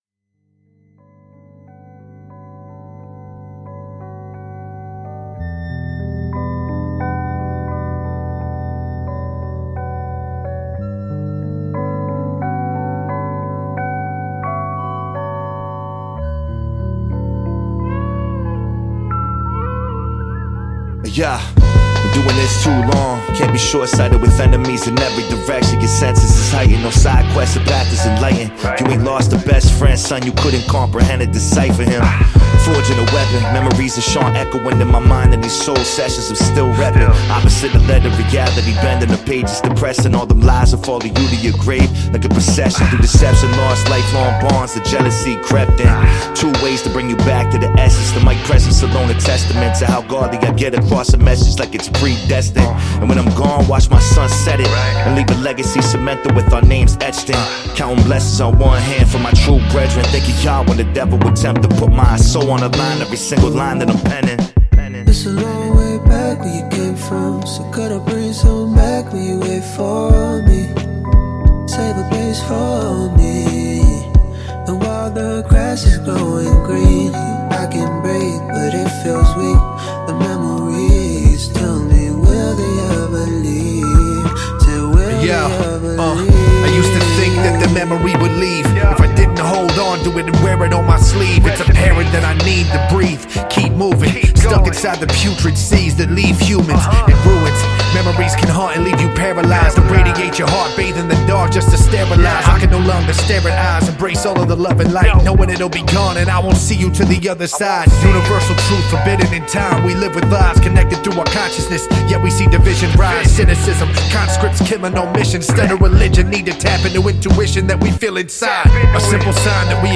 Your Source For Hip Hop News
hauntingly beautiful jazz inspired musical backdrop
somber, reflective and relatable verses